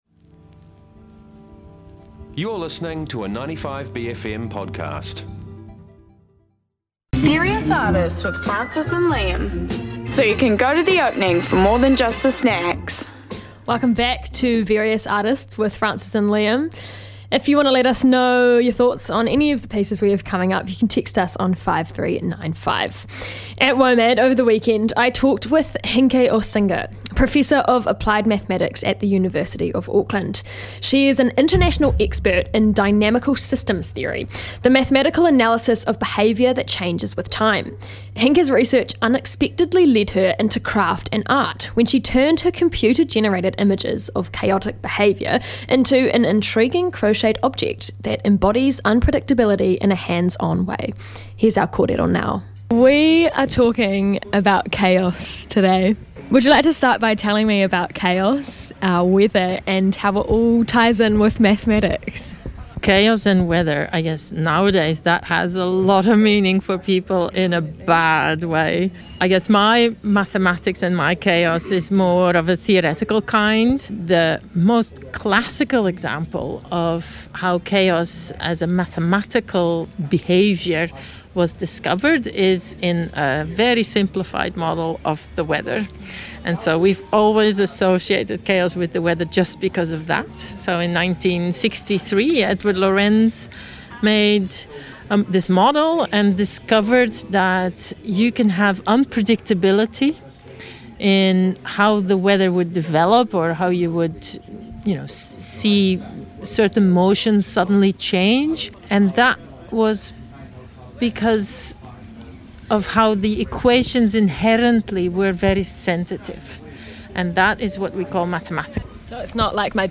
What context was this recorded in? At WOMAD